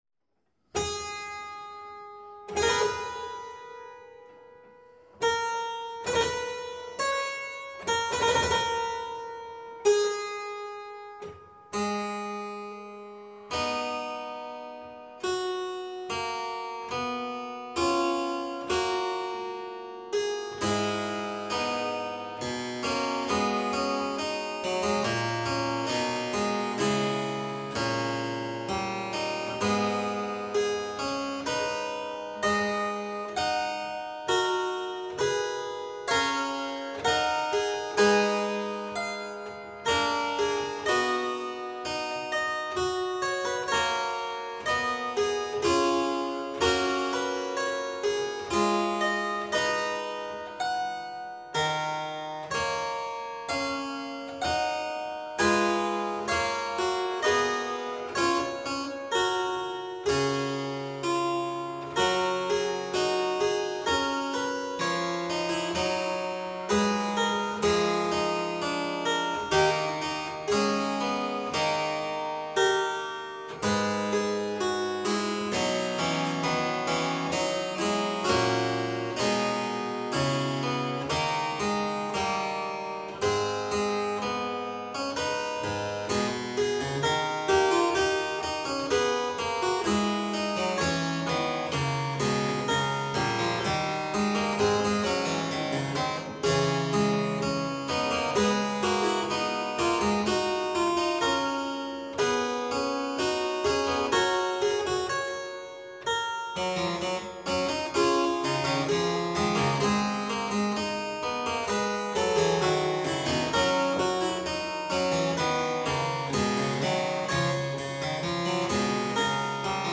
harpsichordist and conductor
One mostly finds two imitative voices against long notes in Gothic style.